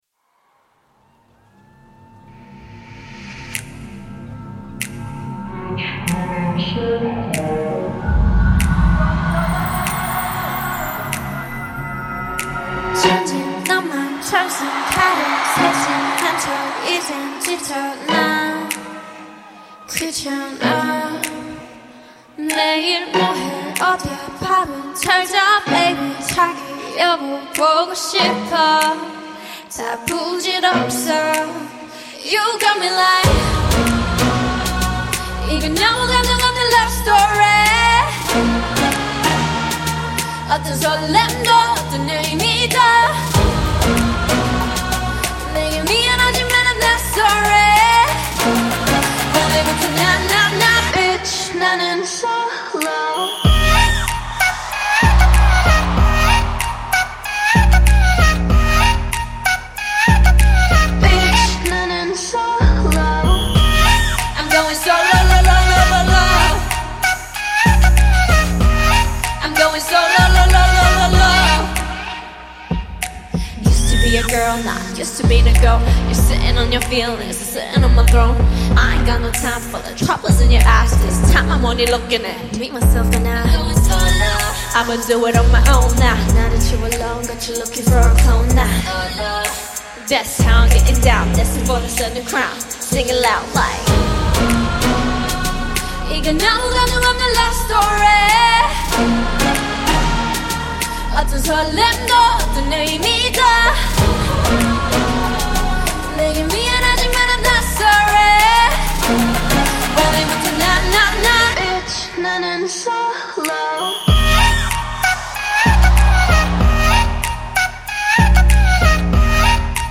ژانر: K-pop